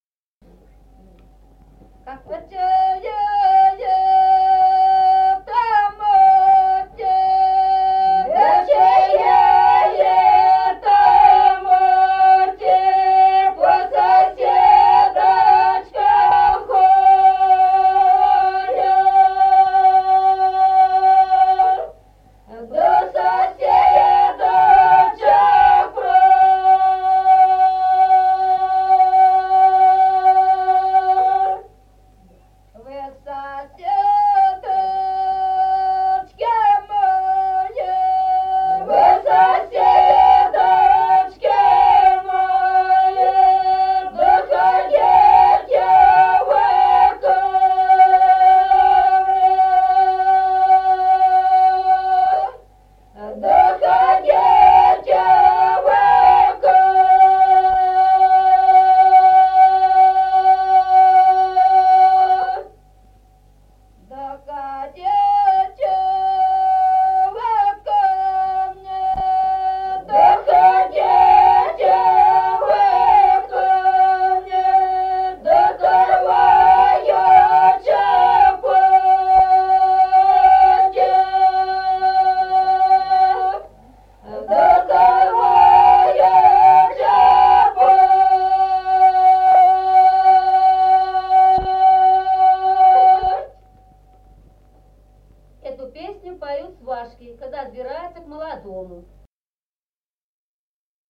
Народные песни Стародубского района «Да чия это мати», свадебная.
1953 г., с. Остроглядово.